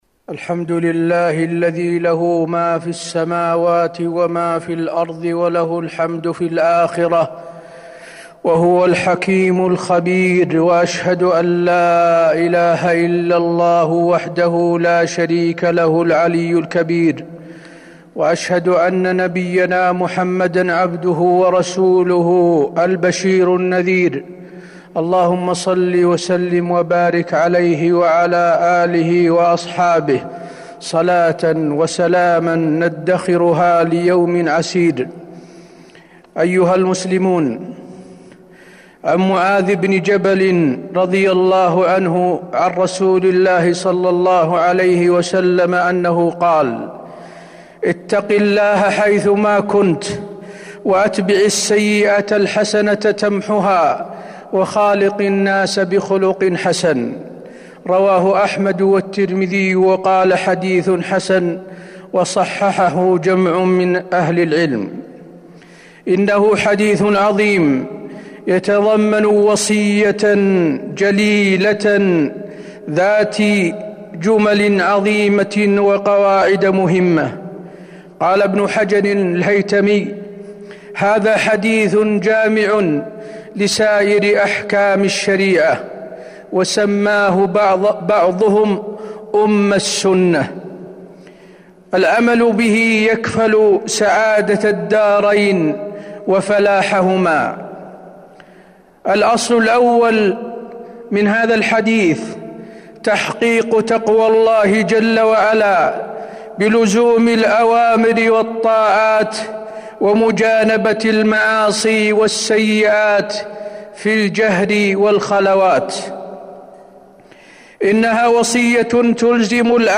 تاريخ النشر ١٦ محرم ١٤٤٢ هـ المكان: المسجد النبوي الشيخ: فضيلة الشيخ د. حسين بن عبدالعزيز آل الشيخ فضيلة الشيخ د. حسين بن عبدالعزيز آل الشيخ اتق الله حيثما كنت